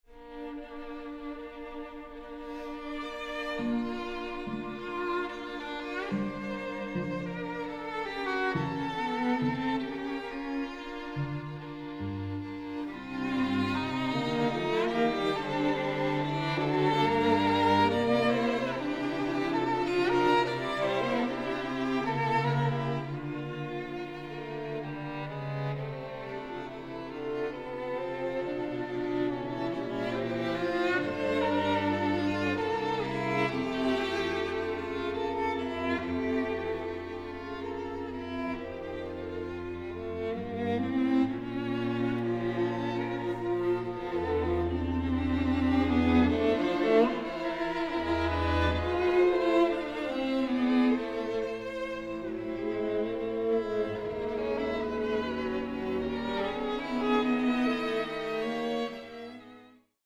String Quartet in D major
III. Andante